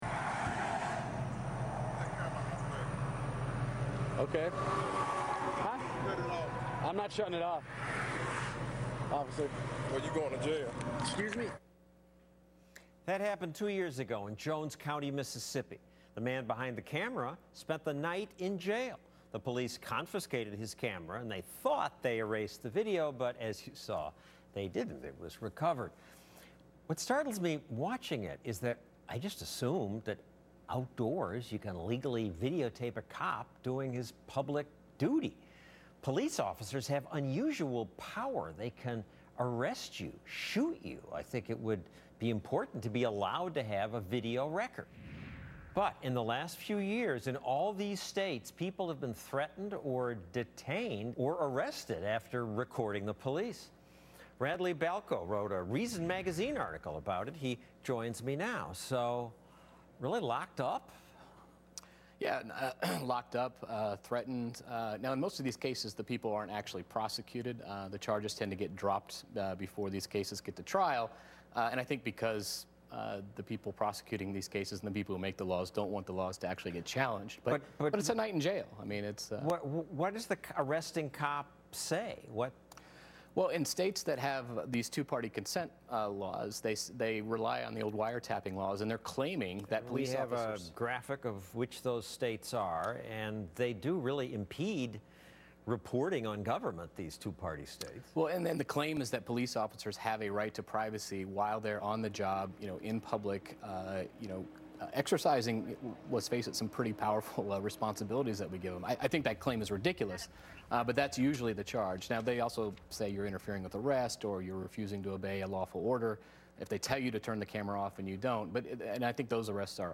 Reason Senior Editor Radley Balko appeared on Fox Business' Stossel to discuss how technology has made it easier for people to film police misconduct and how cops have declared a war on cameras.